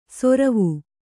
♪ soravu